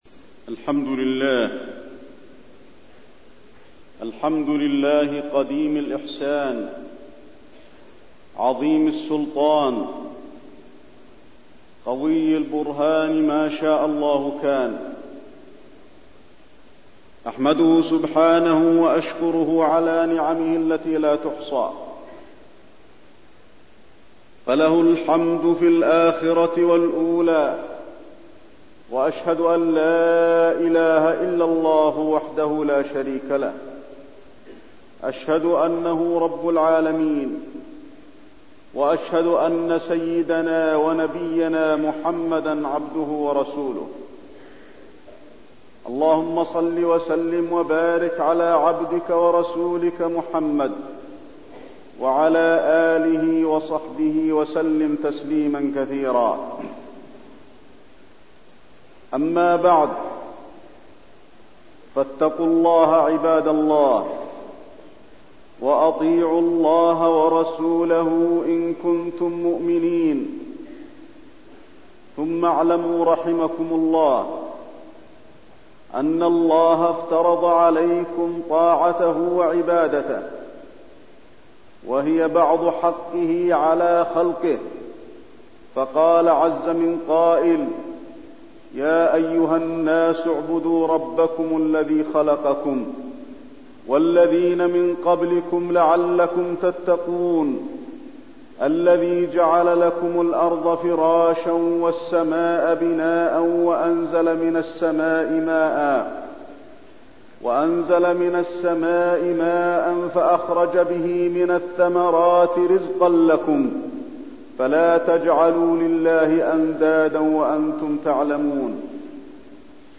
تاريخ النشر ٦ شعبان ١٤٠٥ هـ المكان: المسجد النبوي الشيخ: فضيلة الشيخ د. علي بن عبدالرحمن الحذيفي فضيلة الشيخ د. علي بن عبدالرحمن الحذيفي الإحسان